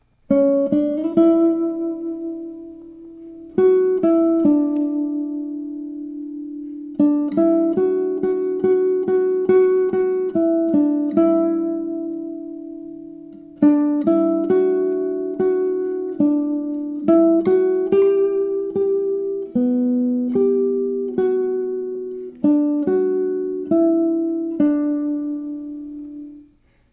He Is Lord has two sharps in the key signature: F sharp and C sharp (key of D major).
He Is Lord | Melody only.